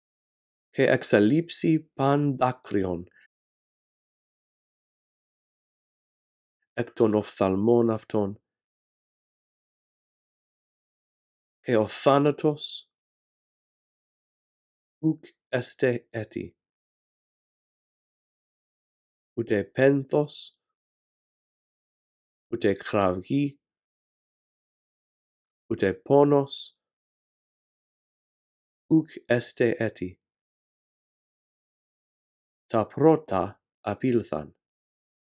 In this audio track, I read through verse 4 a phrase at a time, giving you time to repeat after me.